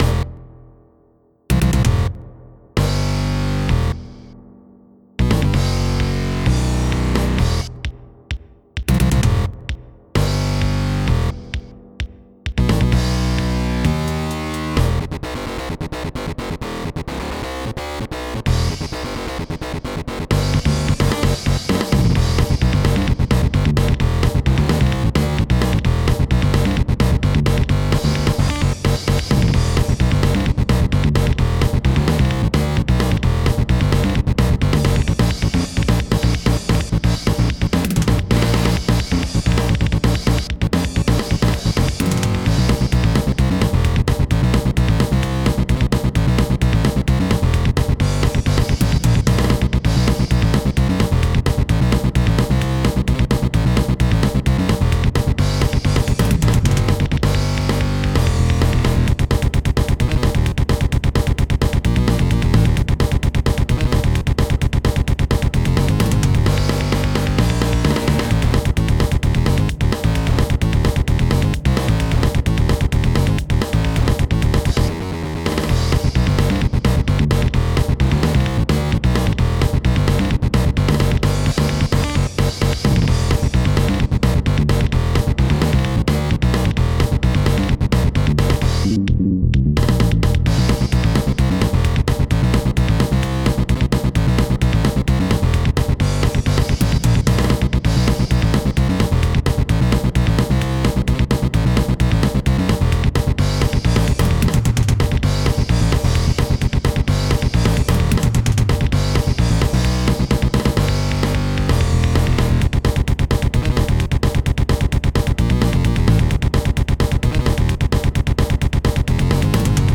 Yeah, more metal.